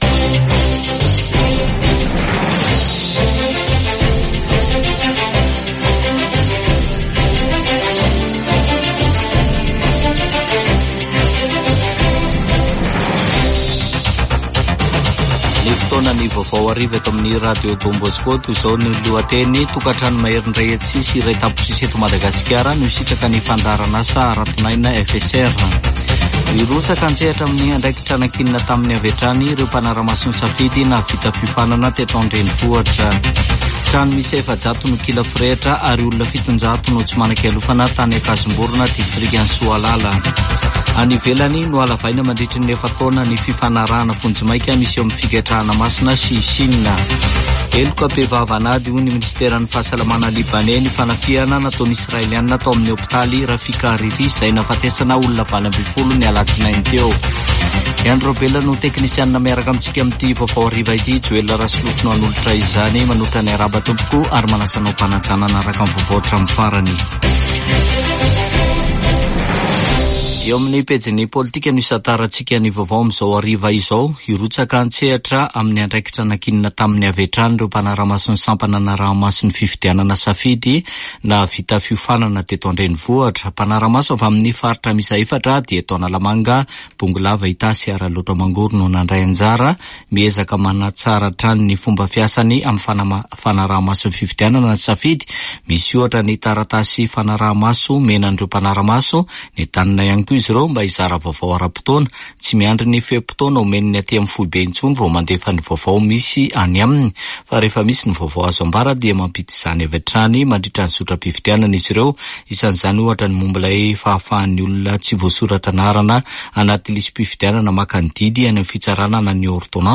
[Vaovao hariva] Alarobia 23 ôktôbra 2024